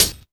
Index of /90_sSampleCDs/300 Drum Machines/Akai MPC-500/1. Kits/Garage Kit
grg thick hat.WAV